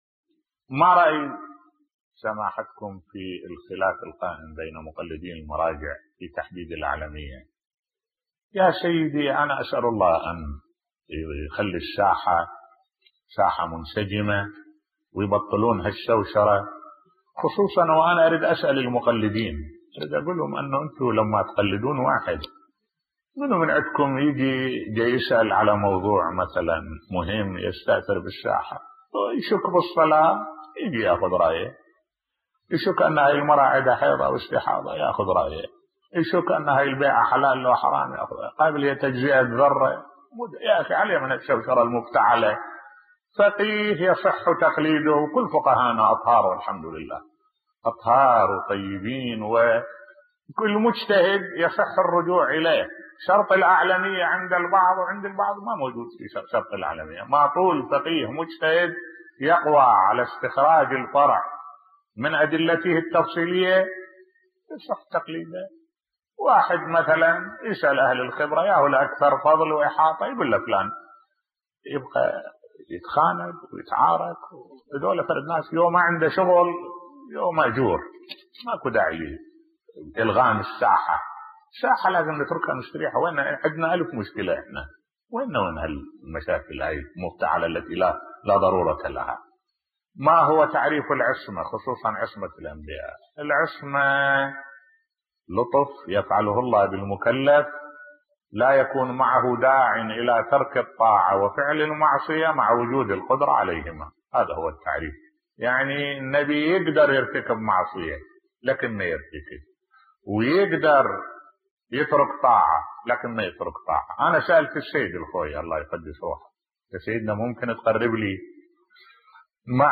ملف صوتی سؤال وجواب - 3 بصوت الشيخ الدكتور أحمد الوائلي